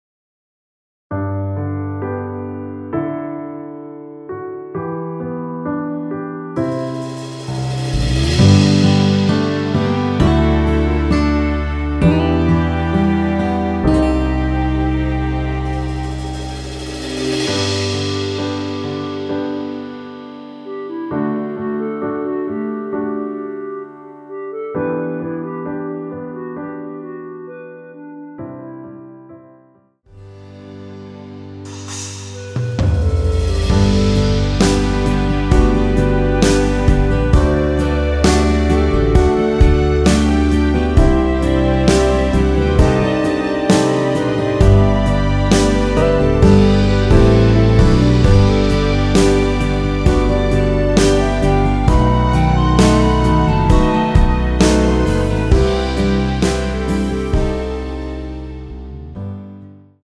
원키에서(+3)올린 멜로디 포함된 MR입니다.
Bb
앞부분30초, 뒷부분30초씩 편집해서 올려 드리고 있습니다.
중간에 음이 끈어지고 다시 나오는 이유는